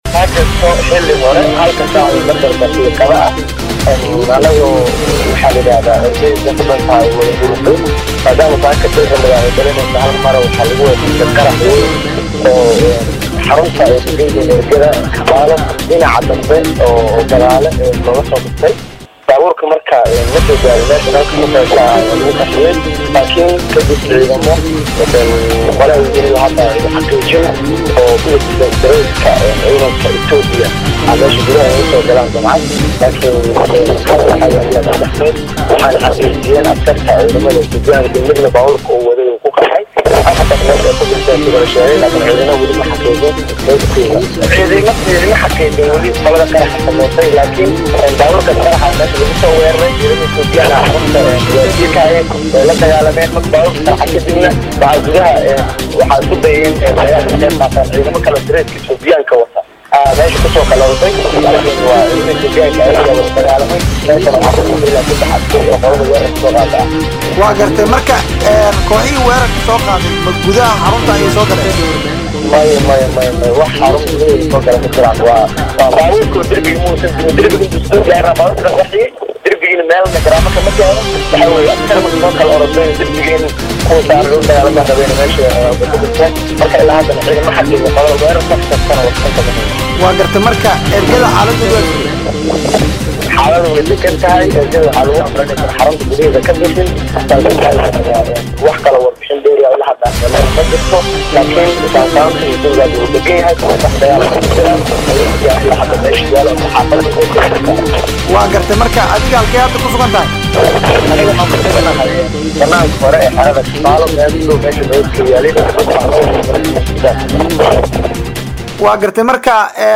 mid kamid ah Dadka jooga Xarunta Ergada Shirka ayaa Mustaqbal Wareysi uu siiyay kaga warbixiyay waxa meesha ka dhacay Saaka.